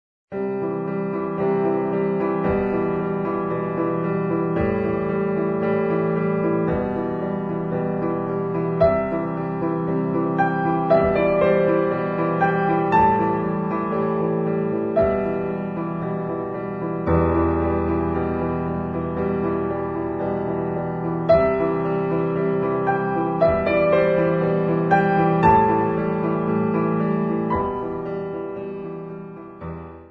Besetzung: Klavier